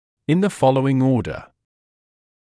[ɪn ðə ‘fɔləuɪŋ’ɔːdə][ин зэ ‘фолоуин ‘оːдэ]в следующем порядке